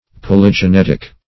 Polygenetic \Pol`y*ge*net"ic\, a.